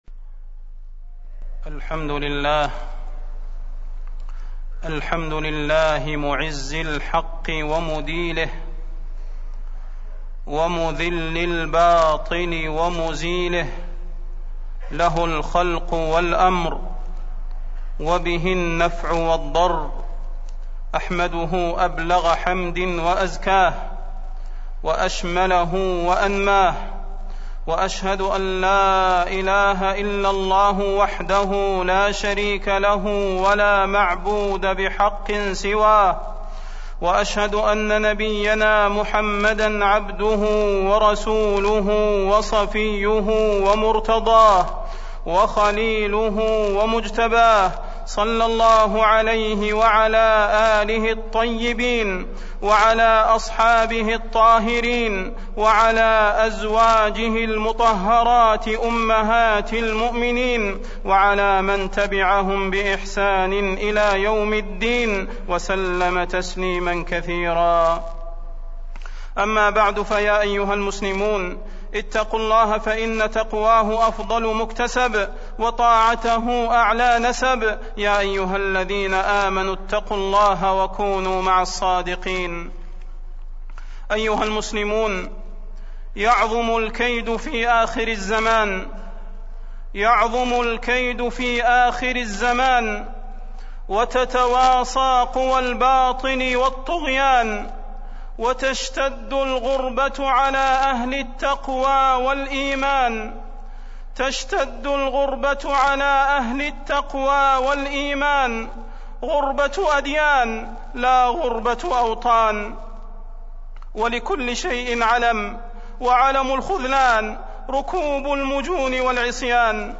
تاريخ النشر ٢٧ ربيع الأول ١٤٢٦ هـ المكان: المسجد النبوي الشيخ: فضيلة الشيخ د. صلاح بن محمد البدير فضيلة الشيخ د. صلاح بن محمد البدير الأمر بالمعروف والنهي عن المنكر